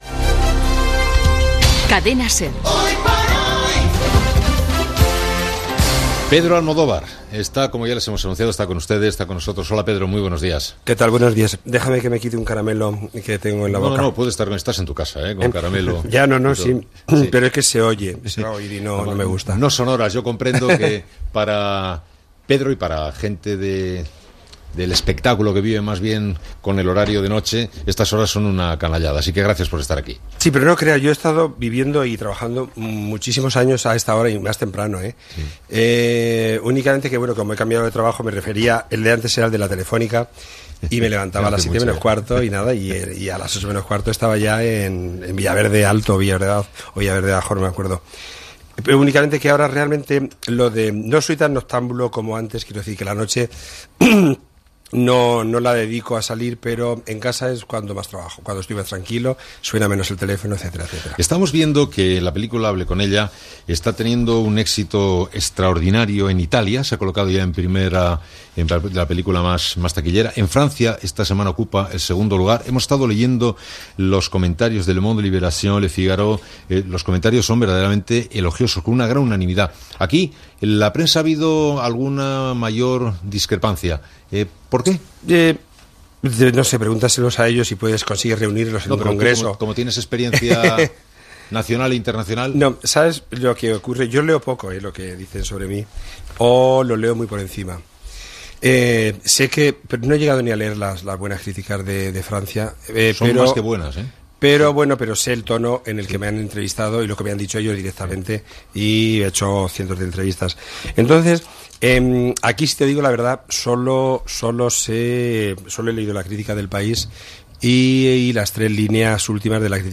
Entrevista al director de cinema Pedro Almodóvar que havia estrenat la pel·lícula "Hable con ella"
Info-entreteniment